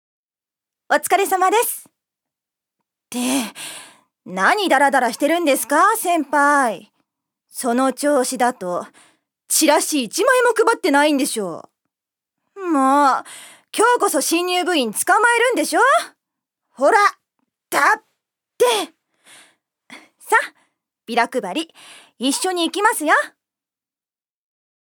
女性タレント
セリフ１